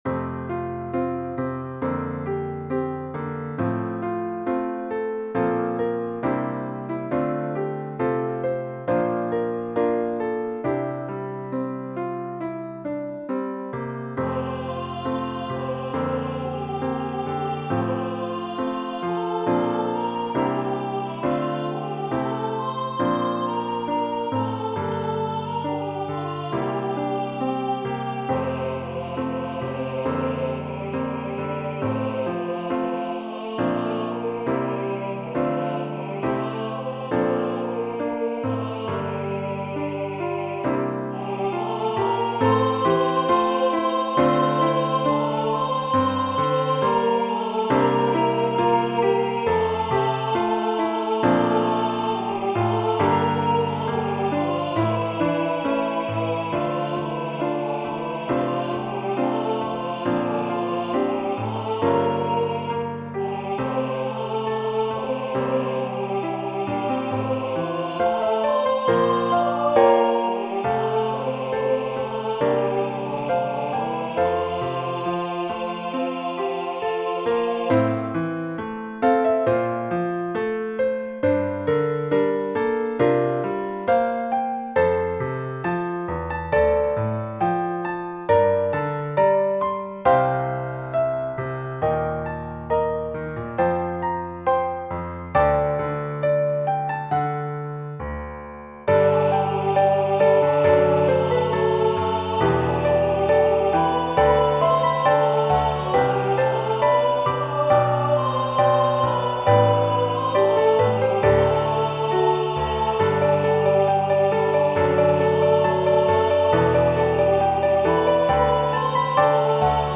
When the Savior Comes Again--Hymn #1002 written for SATB with Piano Accompaniment. This is a hymplicity style arrangement that can be used for pop-up/insta choir.
It starts in the original key of F Major and then changes to G Major.
Voicing/Instrumentation: SATB